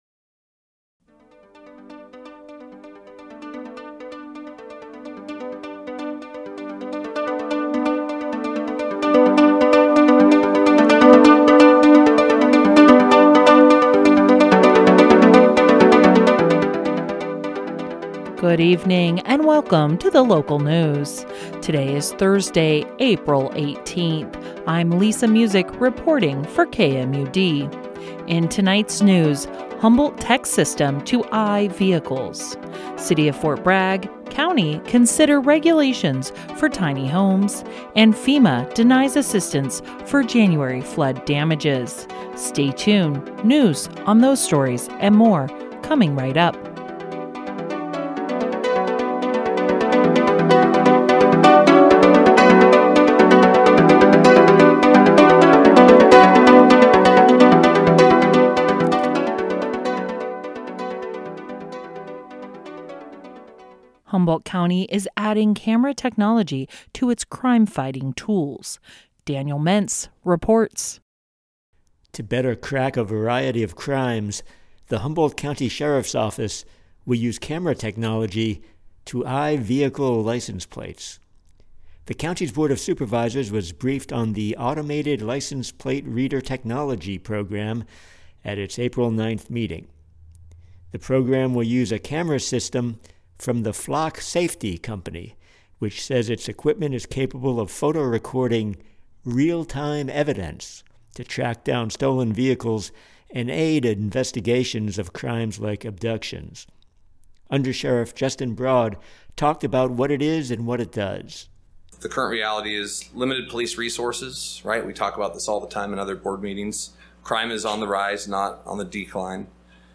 Local news.